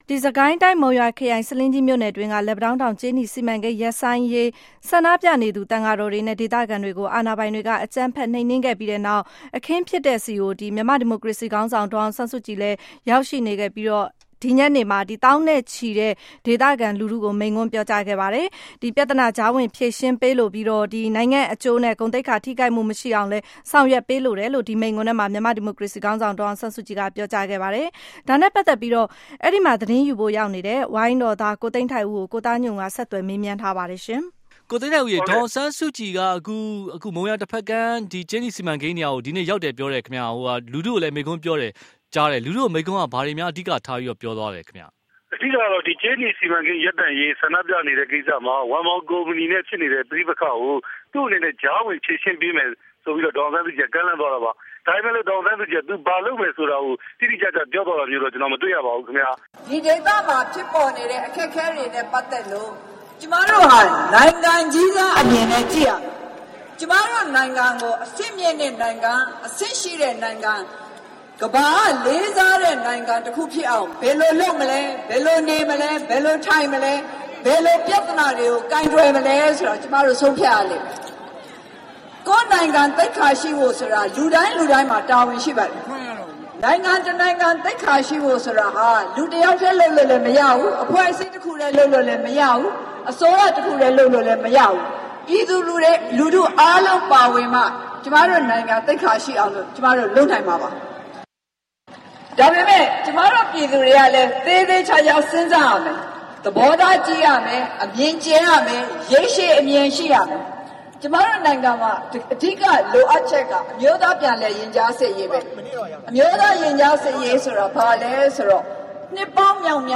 ဒေါ်အောင်ဆန်းစုကြည် လူထုတွေ့ဆုံပွဲ